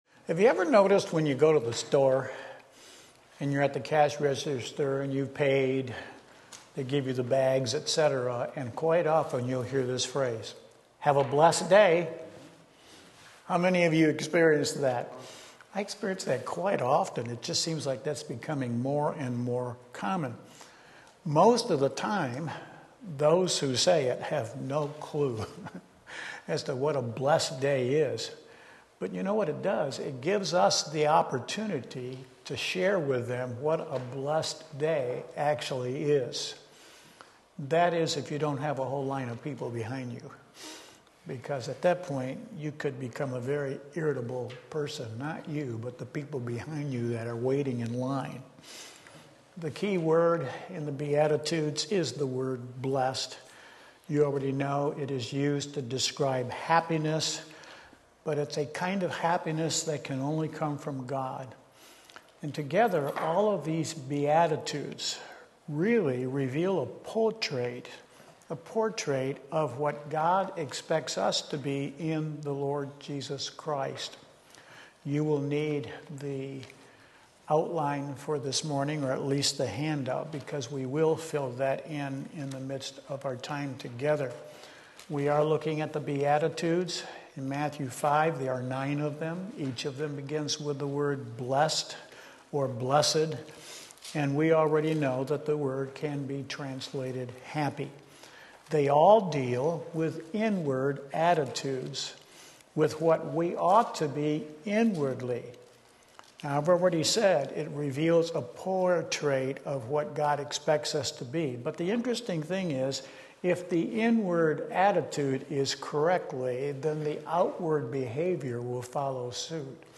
Sermon Link
Sunday School